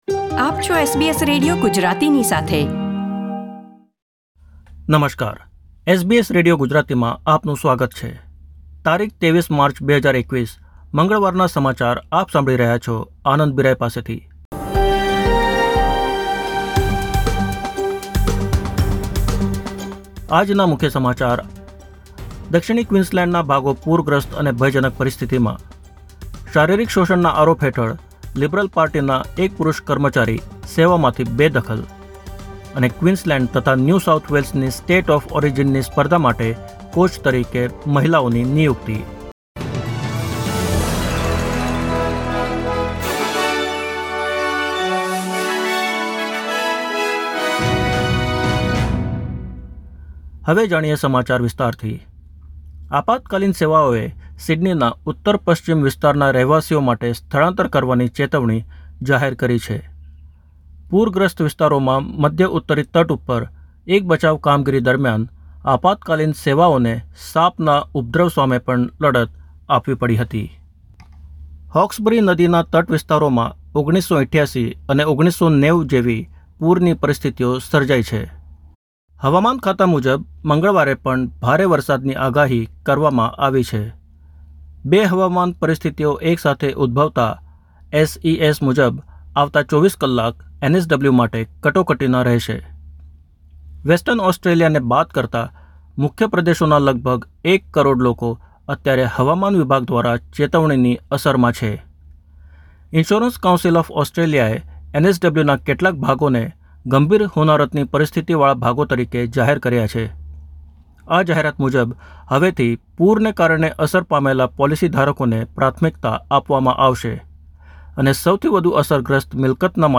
SBS Gujarati News Bulletin 23 March 2021